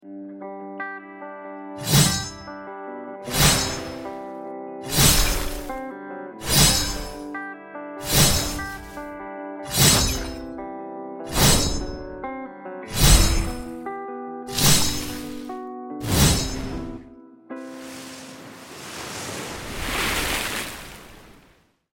효과음으로 듣는 판타지!” 10가지 속성의 판타지 검, 직접 디자인한 게임 효과음으로 비교해보세요!